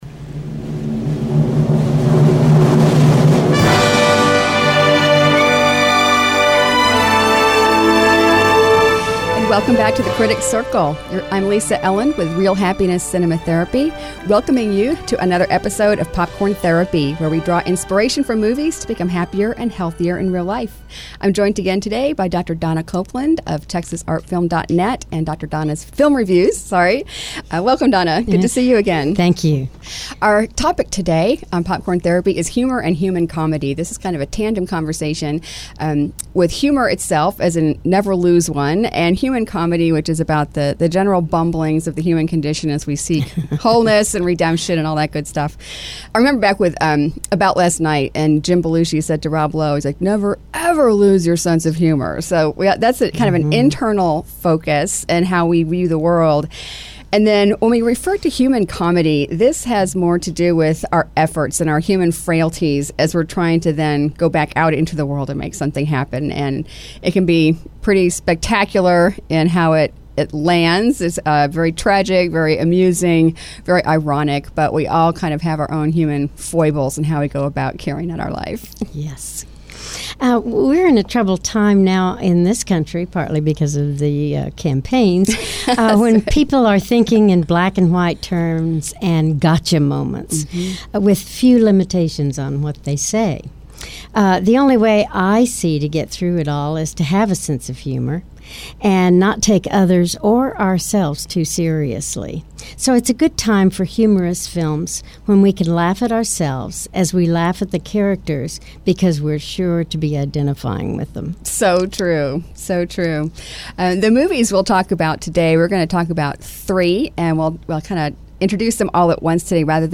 Currently on hiatus, The Critics Circle was the weekly radio show from the Houston Film Critics Society.